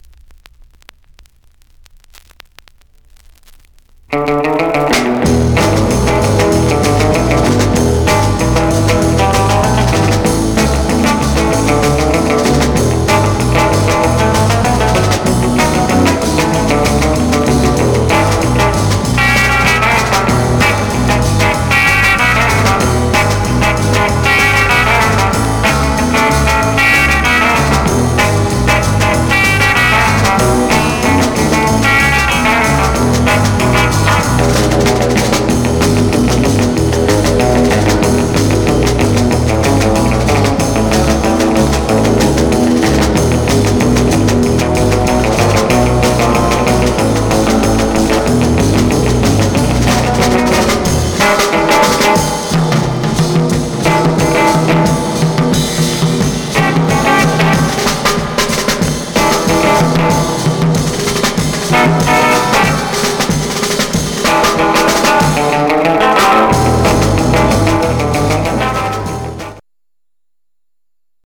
Some surface noise/wear Stereo/mono Mono
R & R Instrumental